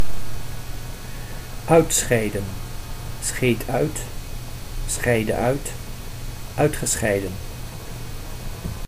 Ääntäminen
IPA: /ˈœy̯tˌsxɛi̯də(n)/